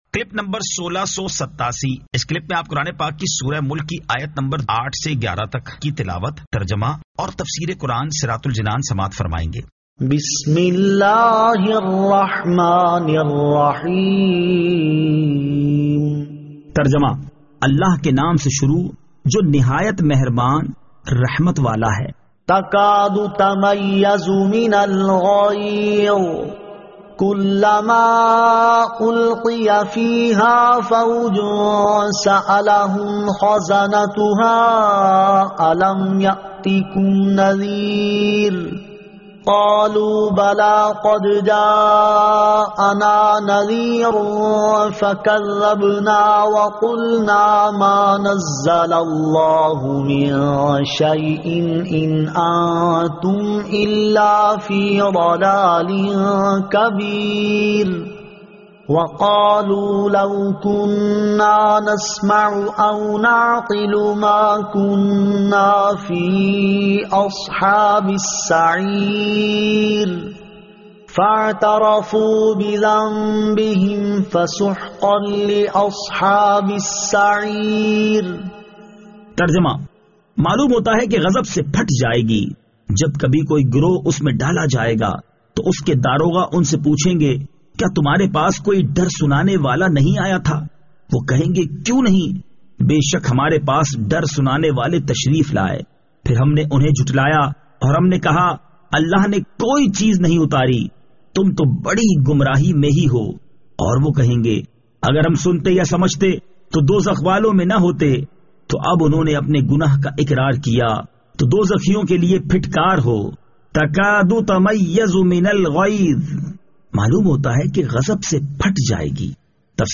Surah Al-Mulk 08 To 11 Tilawat , Tarjama , Tafseer